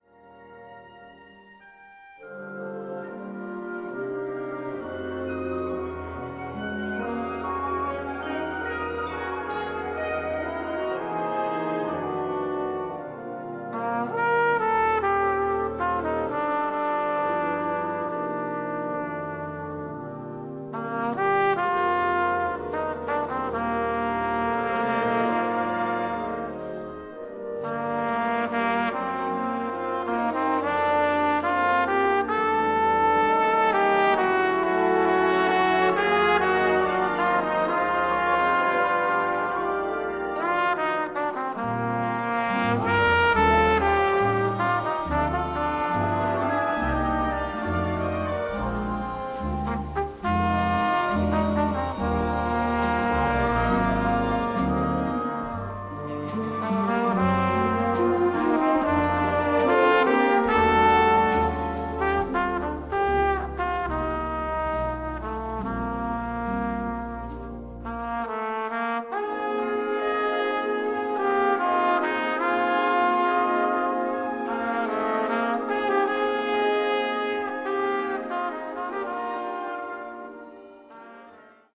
Gattung: Weihnachten
Besetzung: Blasorchester